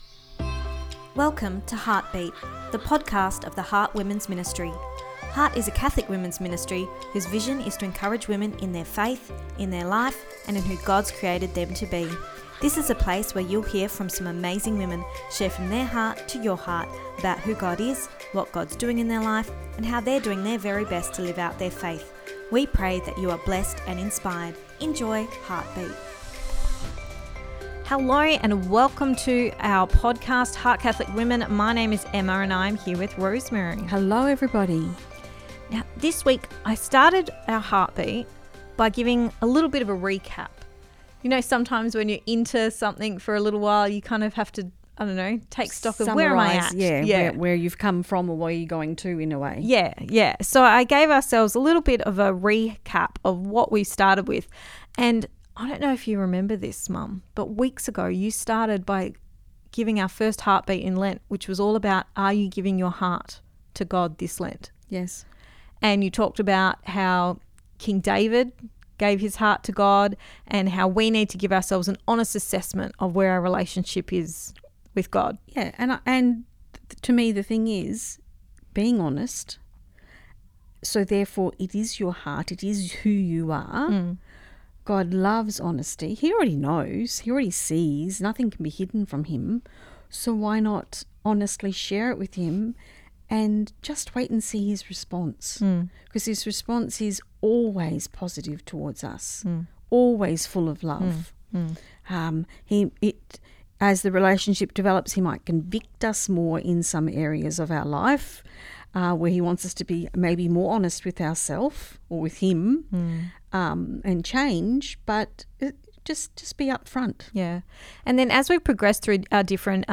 Ep241 Pt2 (Our Chat) – Loving Your Neighbour and Living the Gospel